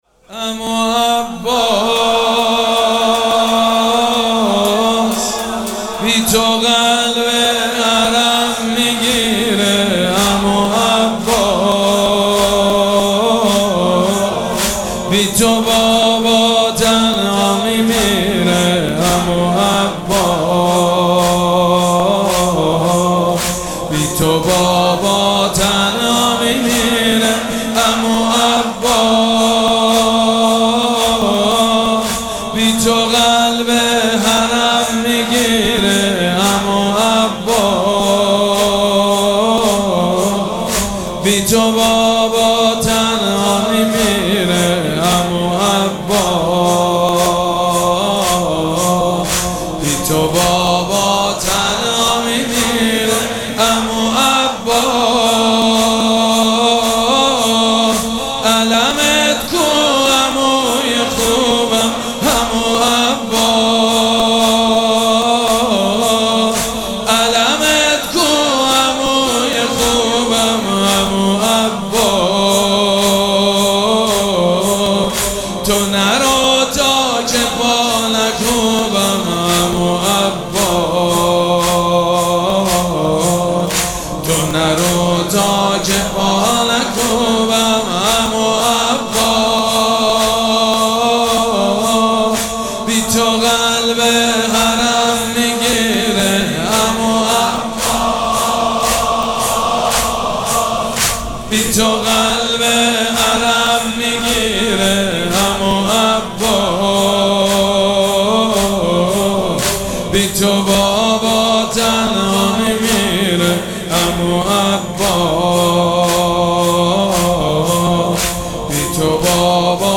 مراسم عزاداری شب نهم محرم الحرام ۱۴۴۷